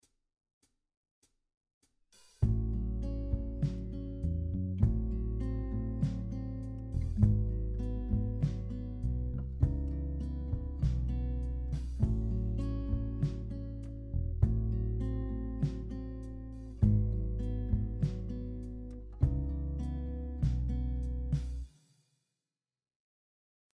Here are some musical examples using shell chords including tabs and audio.
Shell chord progression example 4
This last chord progression is in the key of C# minor.